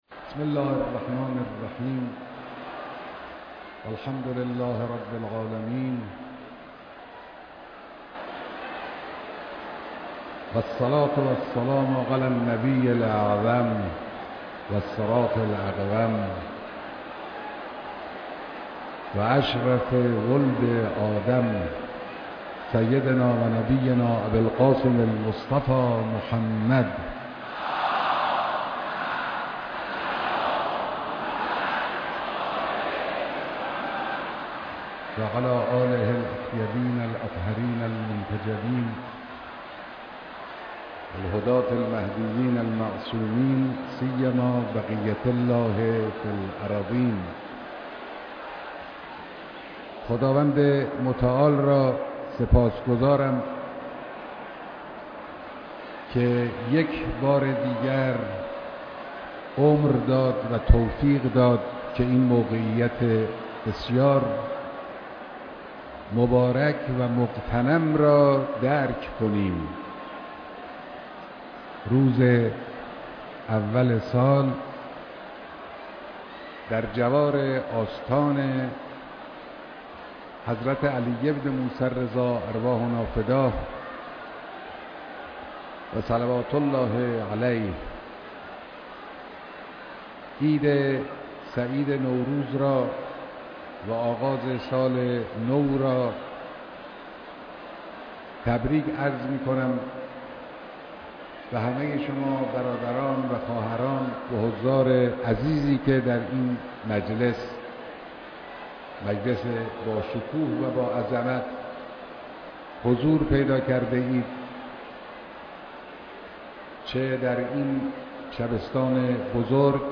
جمع دهها هزار نفر از زائران و مجاوران بارگاه ملکوتی ثامن الحجج علی بن موسی الرضا علیه السلام
بیانات در حرم رضوی در آغاز سال 90